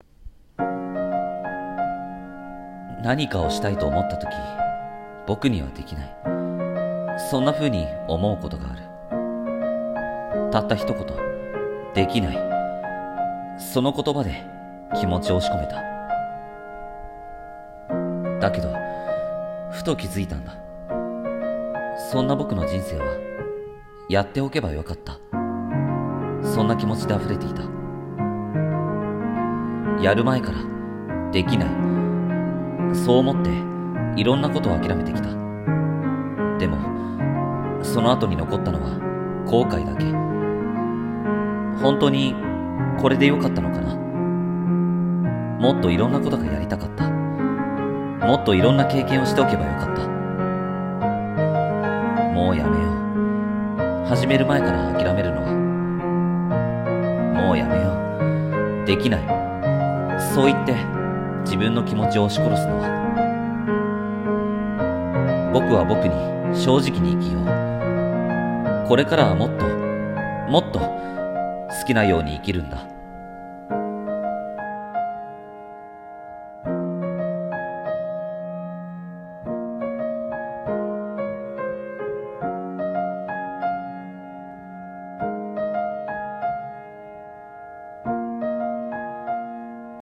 [声劇･朗読]後悔しない人生を